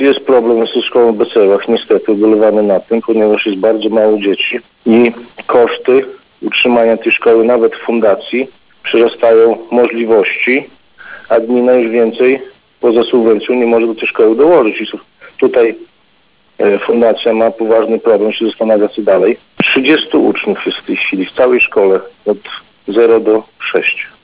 Jak mówi Mariusz Grygieńć, wójt gminy Szypliszki, aktualnie do jednostki uczęszcza 30 uczniów.
Mariusz-Grygieńć-wójt-gminy-Szypliszki.mp3